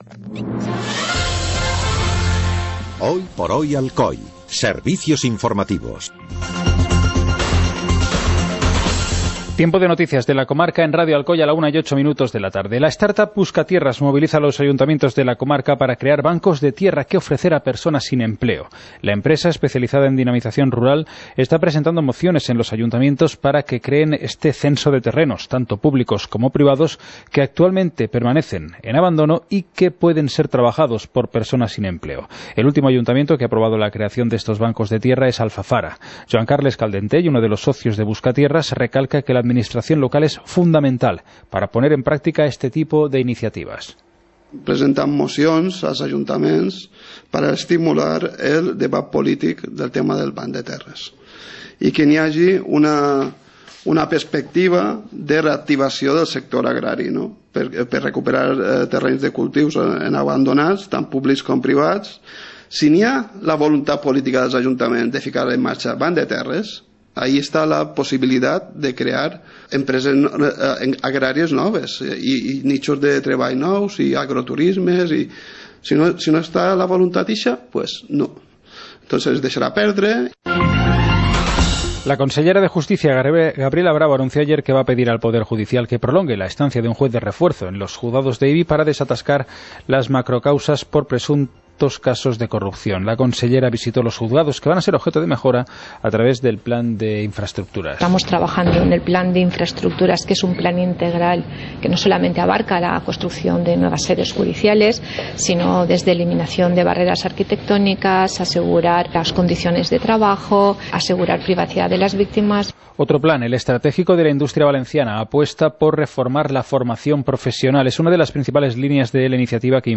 Informativo comarcal - martes, 20 de junio de 2017